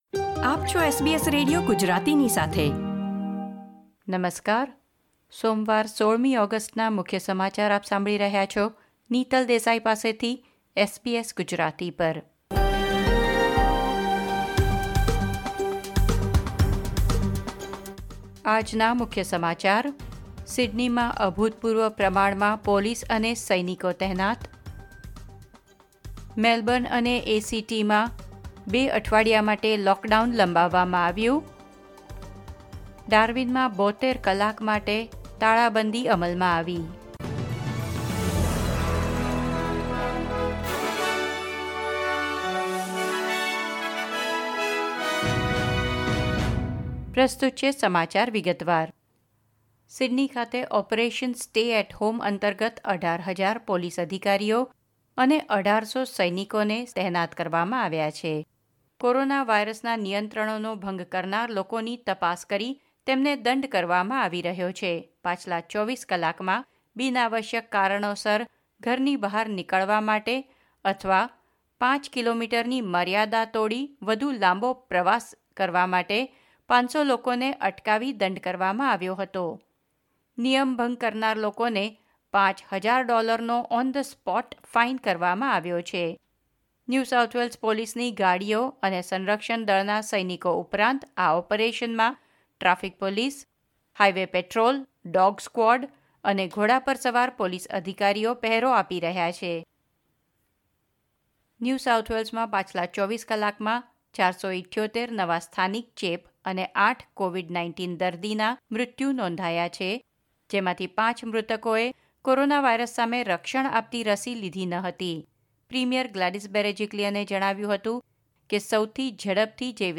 SBS Gujarati News Bulletin 16 August 2021